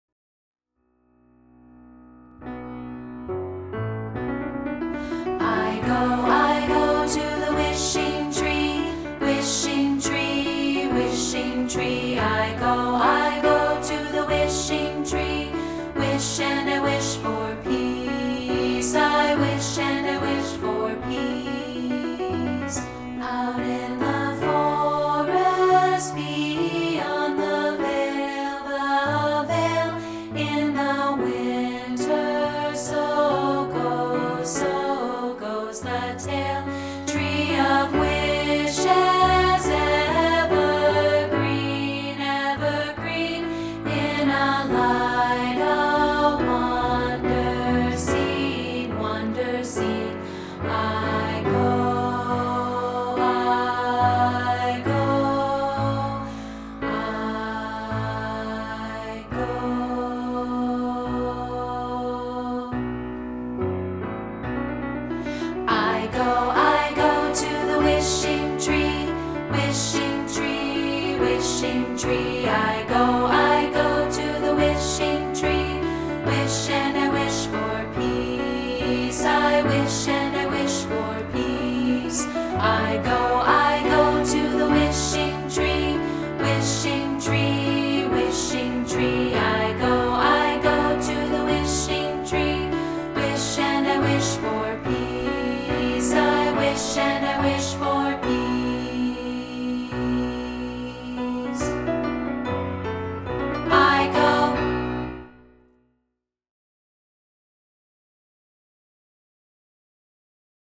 We're offering a rehearsal track of part 2, isolated